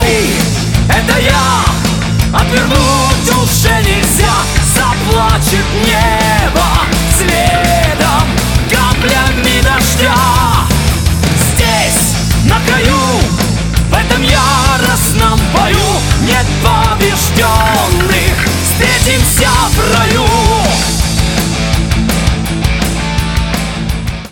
• Качество: 192, Stereo
рок